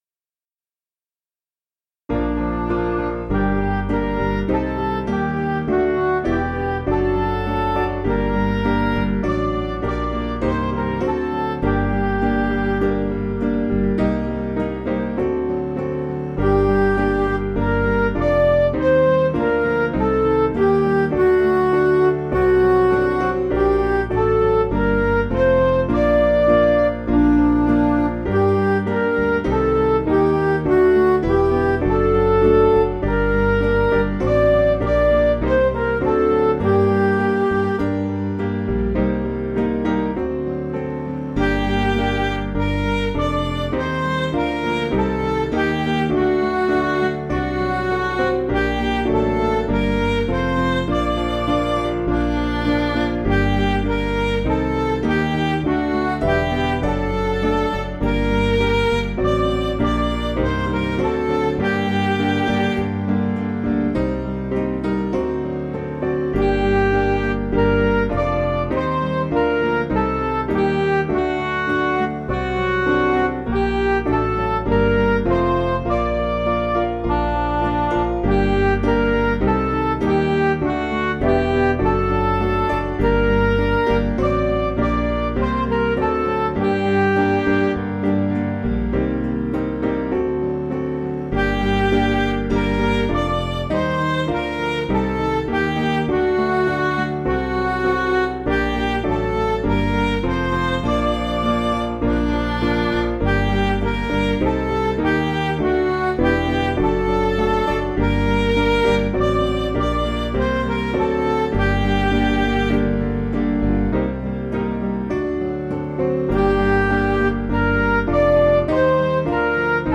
Piano & Instrumental
(CM)   6/Gm